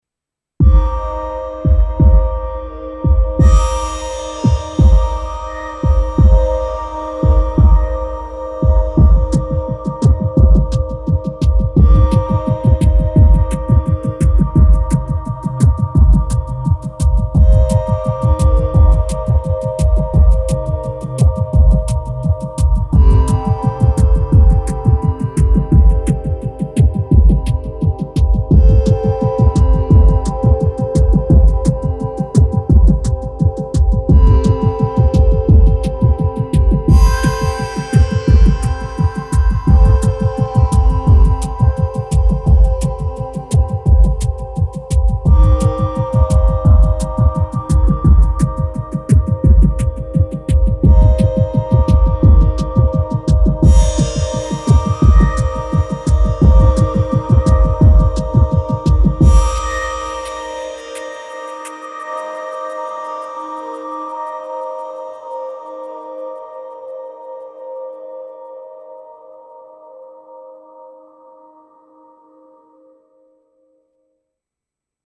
I went for quantity over quality this time :sweat_smile: This is all over the place, but quite firmly in the melodic/tonal camp.
A slow, moody beat with some glassy textures on top